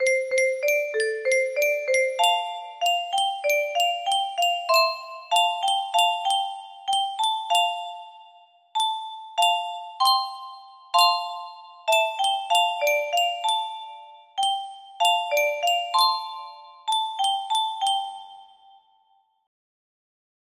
Melody turnt into C